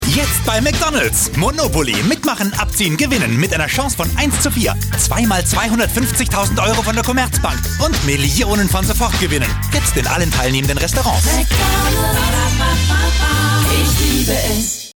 deutscher Sprecher und Schauspieler.
Kein Dialekt
Sprechprobe: Industrie (Muttersprache):
voice over talent german